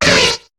Cri de Munja dans Pokémon HOME.